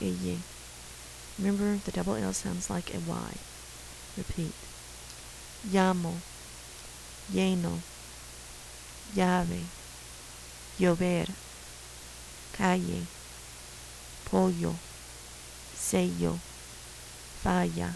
Sounds that are pretty different from English
Sound like h            silent                       sounds like h           sounds like y           sounds like ny